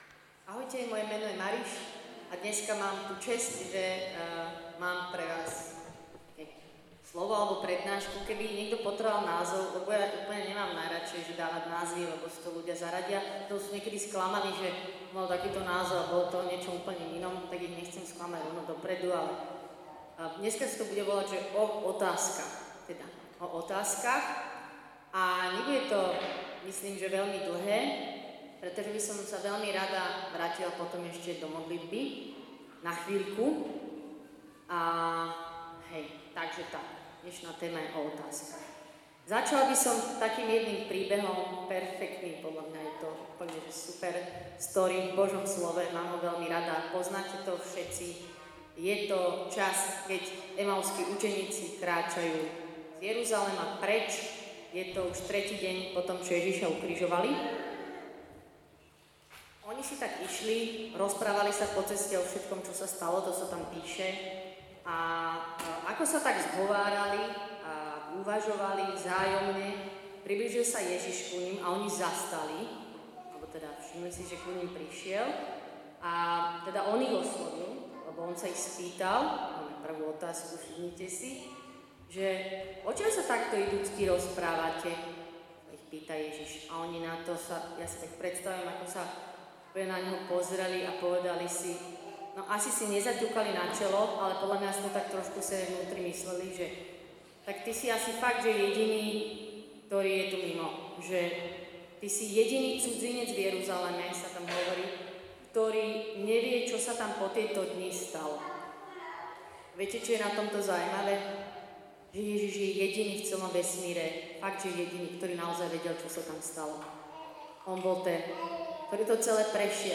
Nahrávka vznikla na otvorenom stretnutí Komunity Eben Ezer, tzv. “Open Eben” v júli 2022 v kostole sv. Margity v Bratislave Lamači.